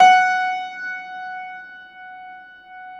53c-pno16-F3.wav